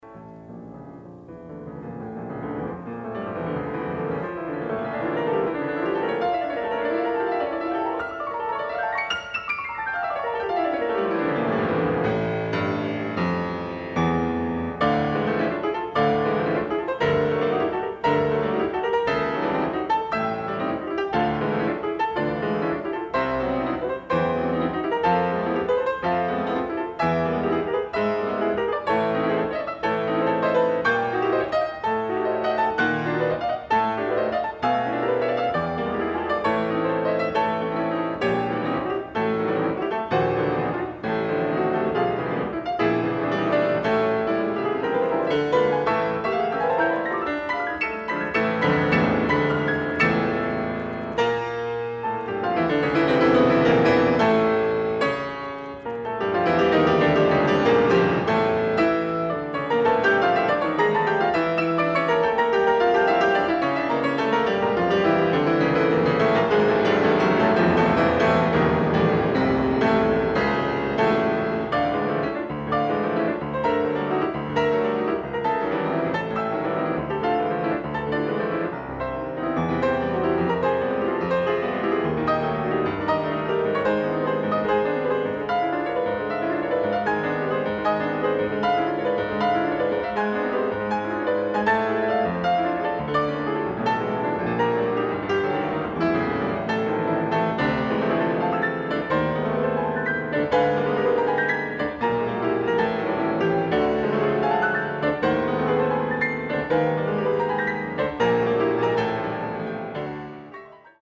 Soloist
Recorded October 2, 1969 in the Ed Landreth Hall, Texas Christian University, Fort Worth, Texas
Etudes
performed music